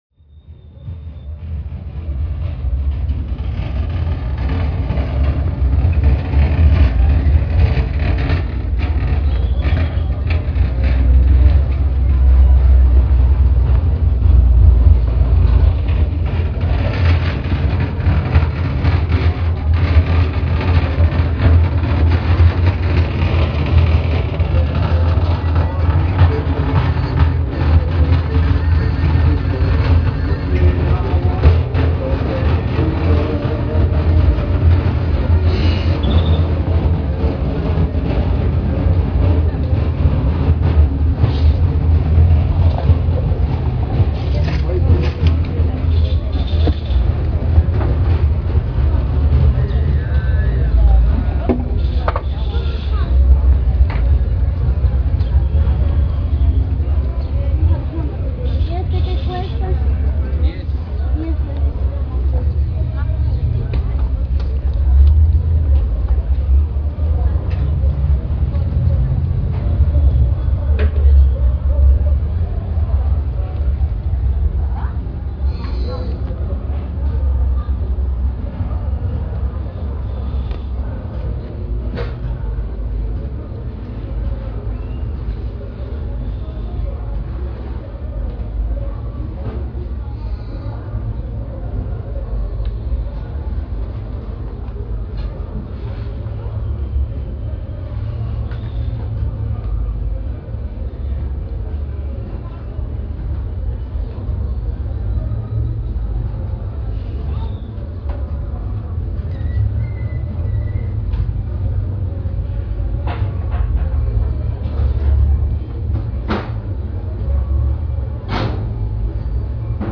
El presente audio es un recorrido en el parque central durante la celebración de la Fiesta Grande de Chiapa de Corzo, aunque fue realizado en la mañana los puestos estaban aún cerrados pero podrán apreciar algunos sonidos interesantes así como el reloj que se encuentra a un lado de La Pila.
Fecha: 23 de enero de 2008 Hora: 10:00 hrs. Equipo: Minidisc NetMD MD-N707, micrófono de construcción casera (más info)